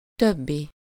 Ääntäminen
Ääntäminen Tuntematon aksentti: IPA: /ˈtøbːi/ Haettu sana löytyi näillä lähdekielillä: unkari Käännös Ääninäyte Adjektiivit 1. other UK US Substantiivit 2. others US Esimerkit A többi szoba üres.